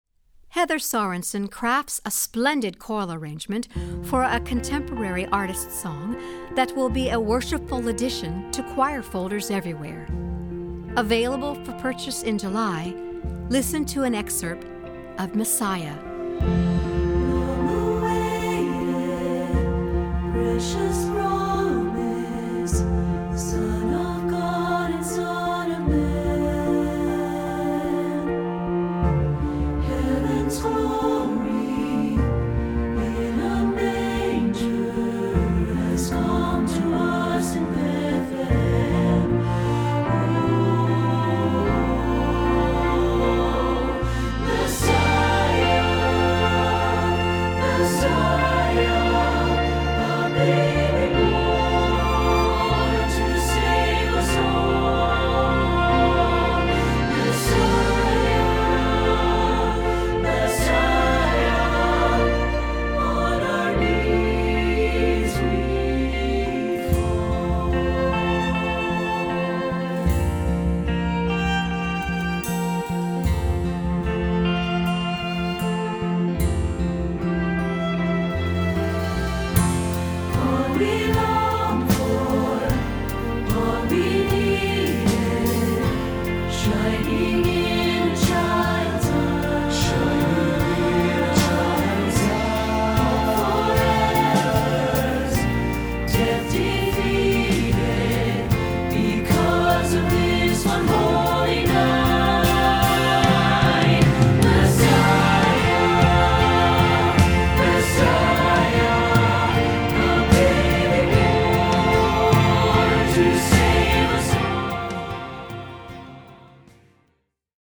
Choral Christmas/Hanukkah
SATB